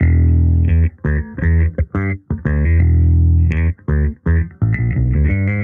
Index of /musicradar/sampled-funk-soul-samples/85bpm/Bass
SSF_JBassProc1_85E.wav